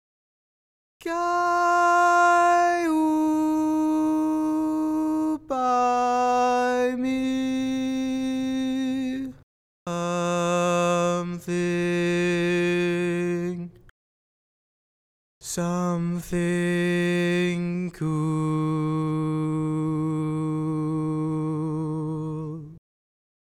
Key written in: E♭ Major
Each recording below is single part only.